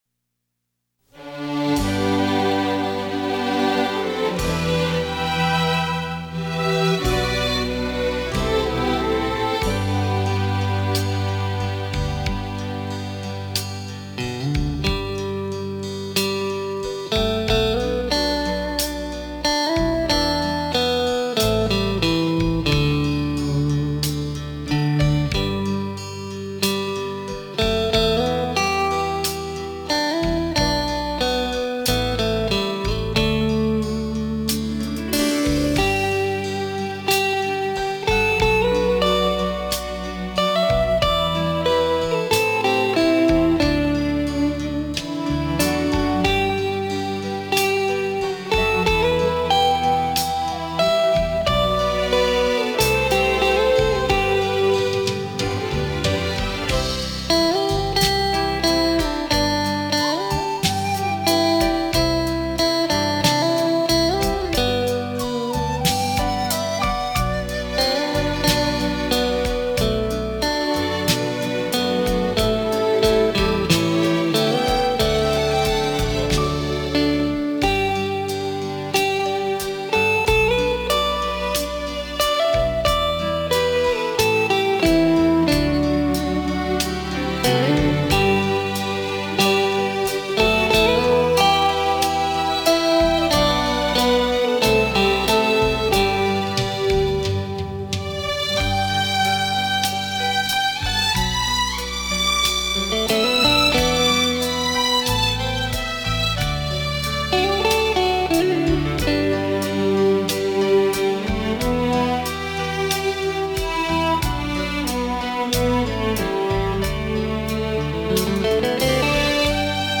专辑语言：纯音